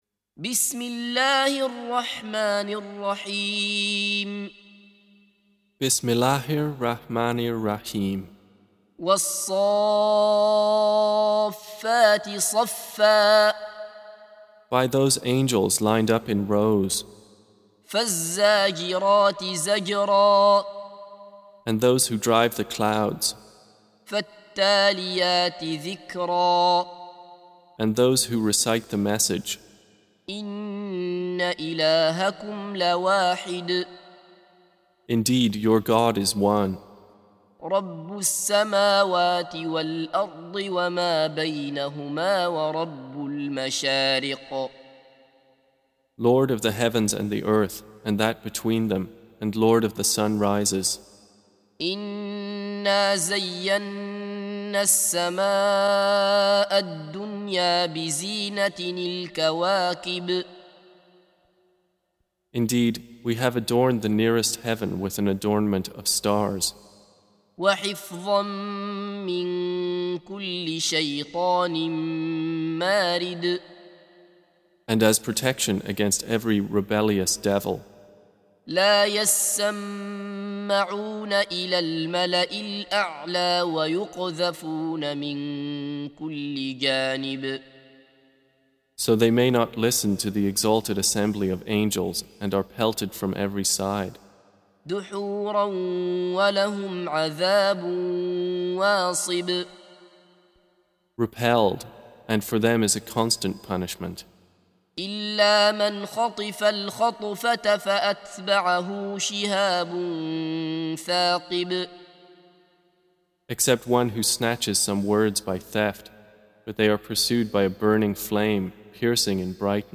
Surah Repeating تكرار السورة Download Surah حمّل السورة Reciting Mutarjamah Translation Audio for 37. Surah As-S�ff�t سورة الصافات N.B *Surah Includes Al-Basmalah Reciters Sequents تتابع التلاوات Reciters Repeats تكرار التلاوات